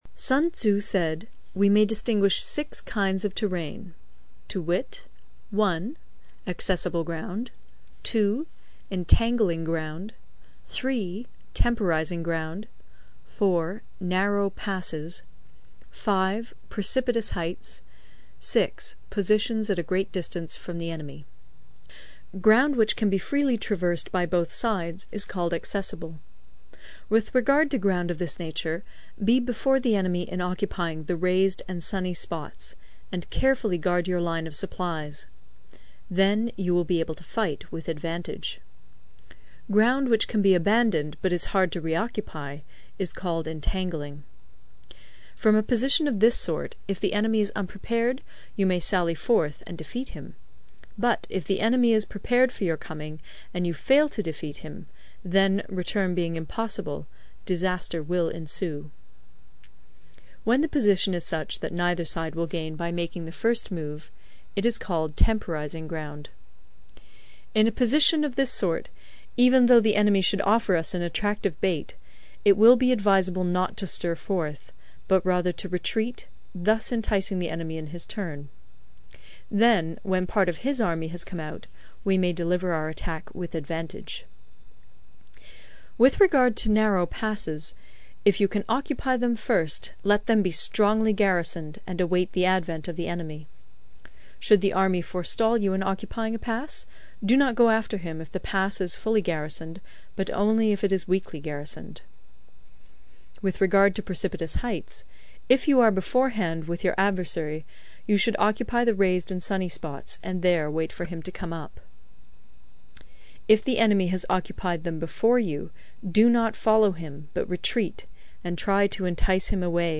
有声读物《孙子兵法》第57期:第十章 地形(1) 听力文件下载—在线英语听力室